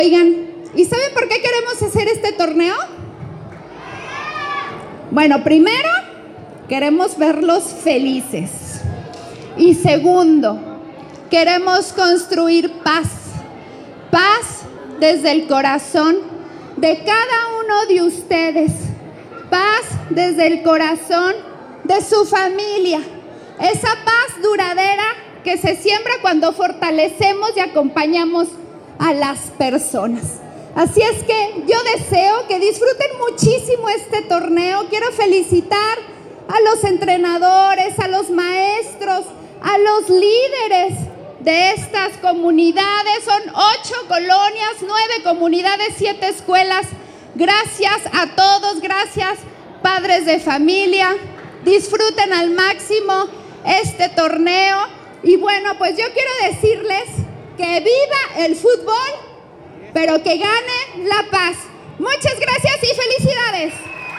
Valeria Alfaro García, presidenta de DIF Municipal